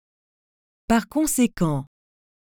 The pronunciation of par conséquent is /paʁ kɔ̃.se.kɑ̃/ (IPA), which sounds roughly like “par kon-se-kahn.”
par-consequent.mp3